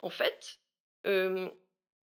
VO_ALL_Interjection_12.ogg